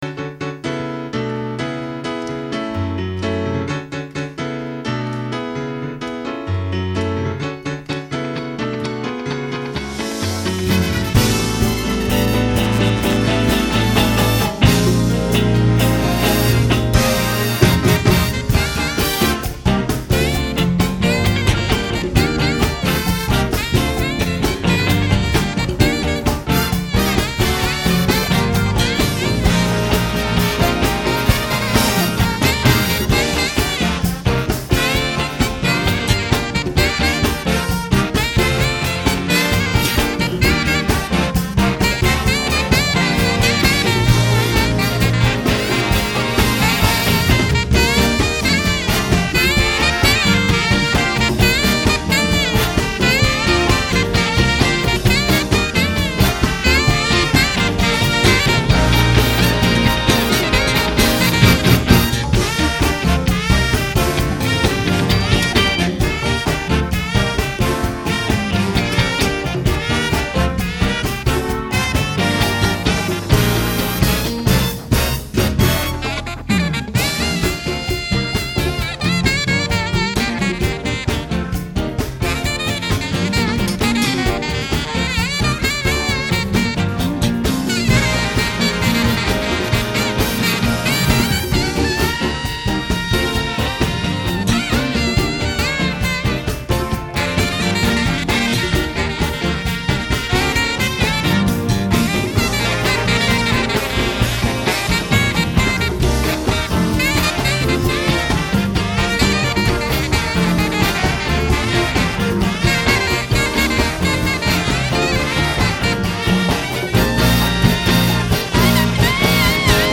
참 멜로디가 매력적이네요